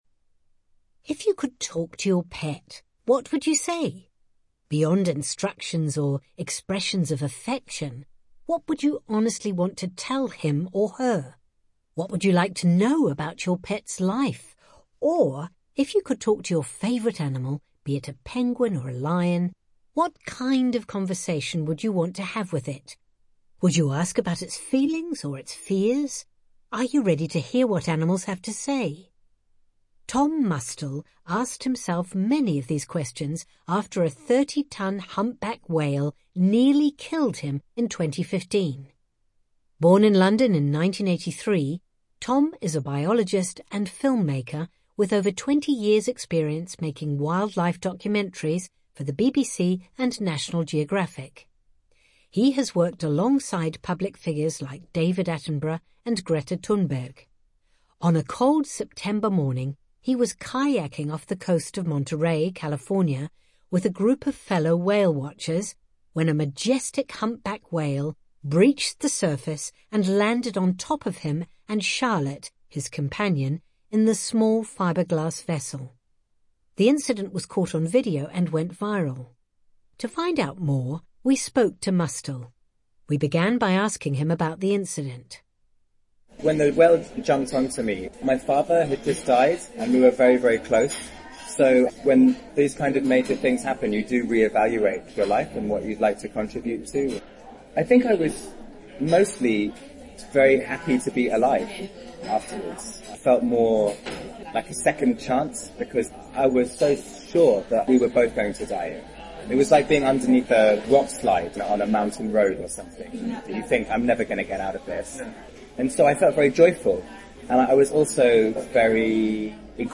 English accent